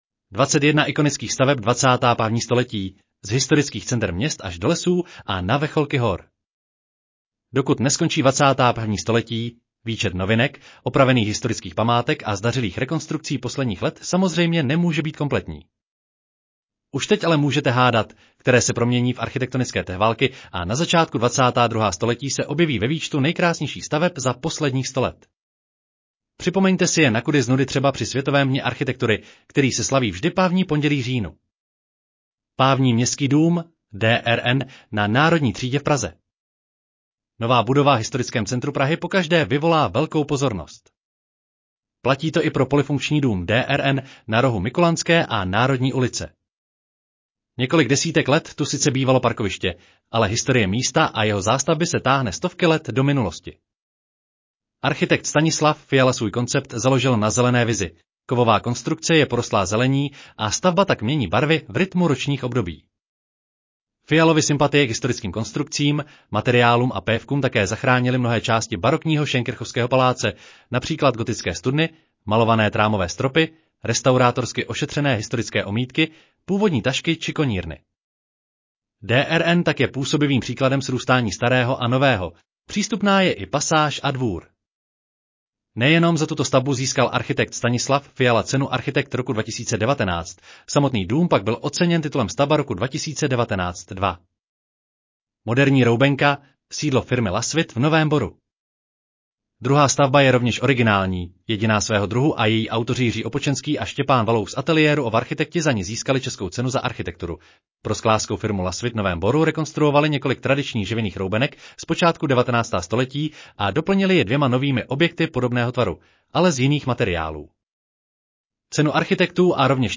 Audio verze článku 21 ikonických staveb 21. století: z historických center měst až do lesů a na vrcholky hor